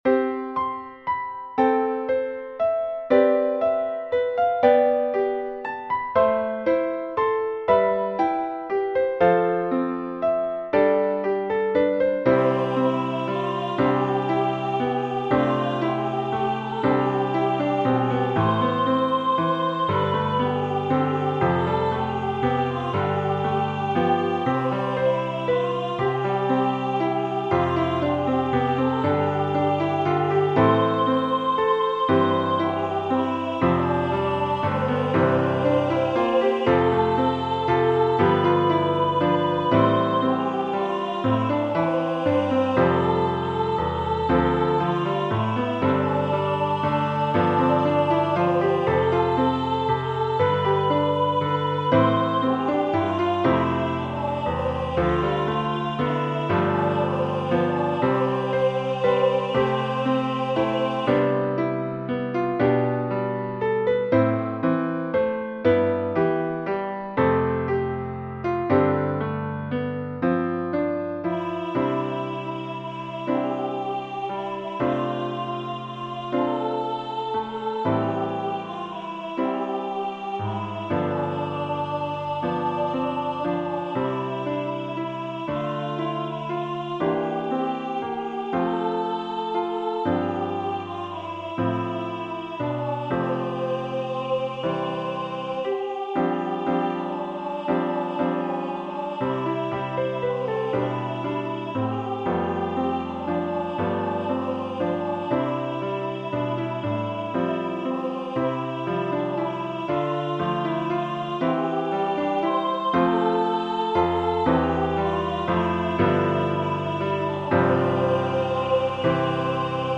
vocal solo with piano accompaniment